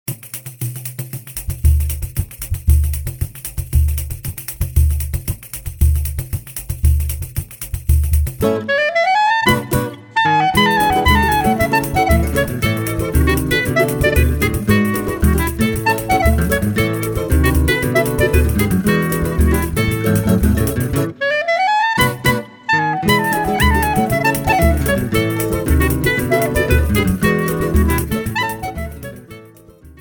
clarinet
Re-recorded in A major